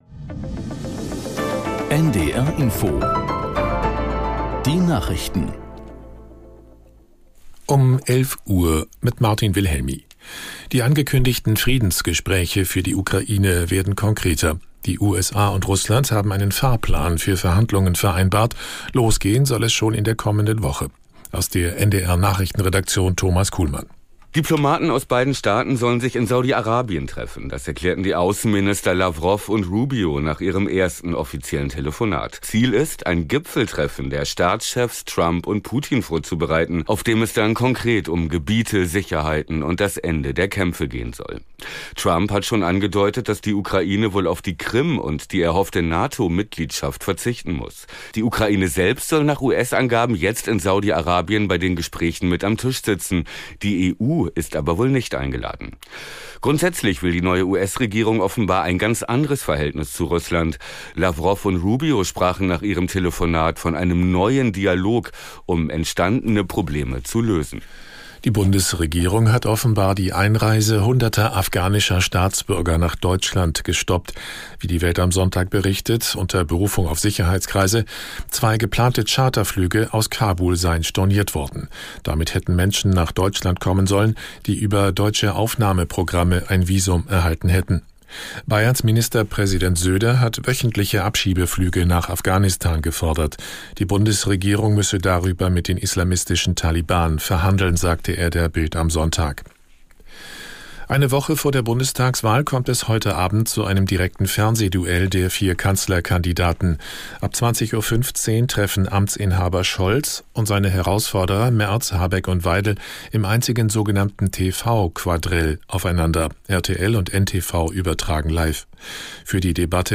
Nachrichten NDR Info Tägliche Nachrichten